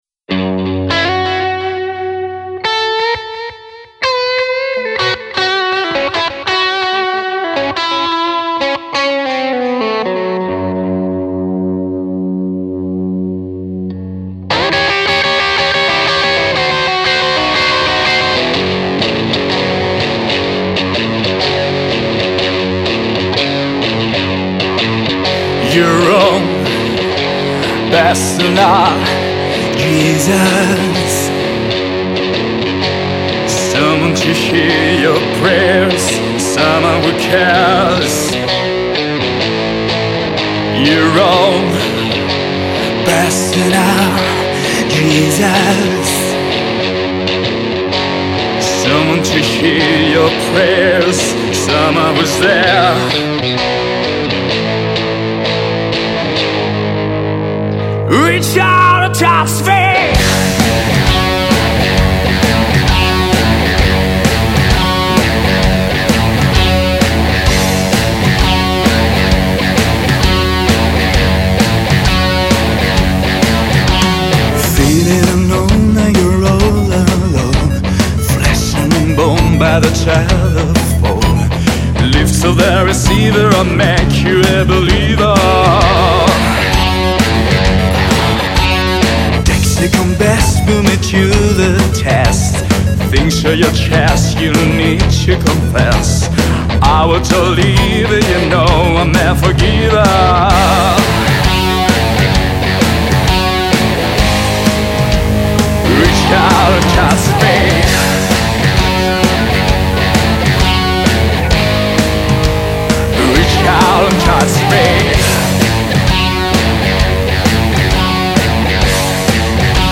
Rock live.